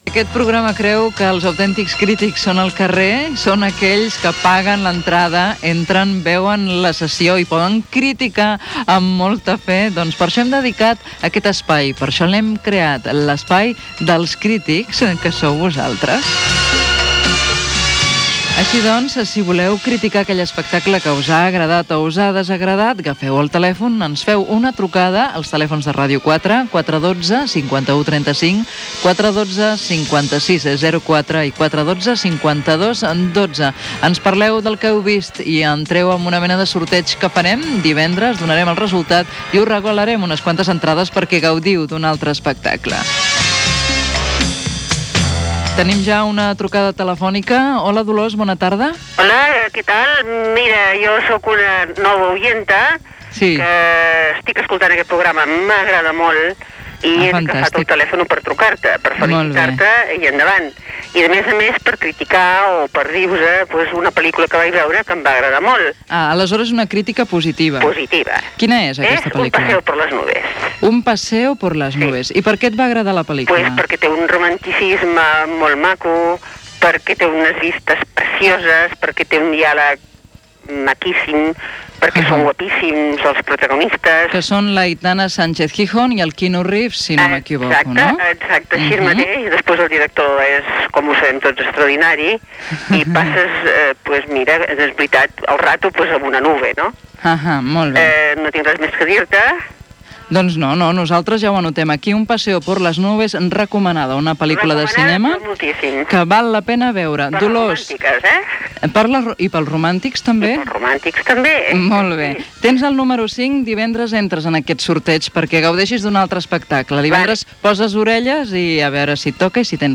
0a71b0a27caabc38c933286eb41ac4e813f0b748.mp3 Títol Ràdio 4 Emissora Ràdio 4 Cadena RNE Titularitat Pública estatal Nom programa Molt lluny de Manhattan Descripció Els crítics de Ràdio 4 i recomanació d'una lectura. Gènere radiofònic Cultura